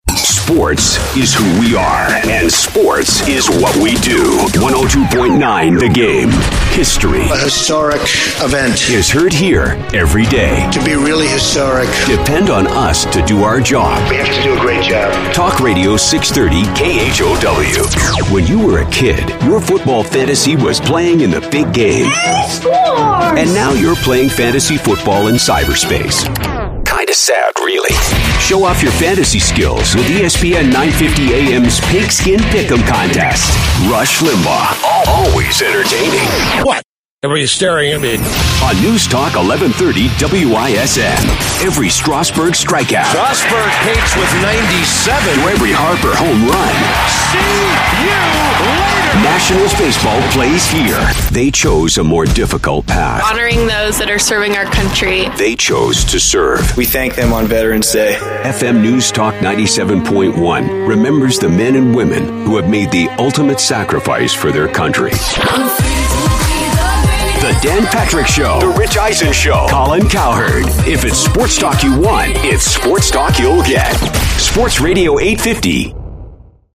English (Canadian)
Radio Imaging
Sennheiser MKH 416 microphone
Custom built voice booth
BaritoneBassDeep
ConfidentGroundedSeriousAuthoritativeConversationalCorporateExperienced